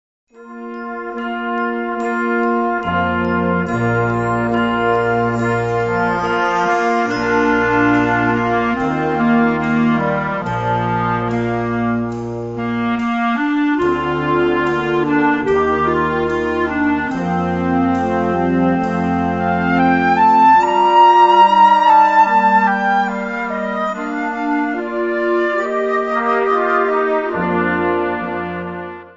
Gattung: Pop-Ballade
Besetzung: Blasorchester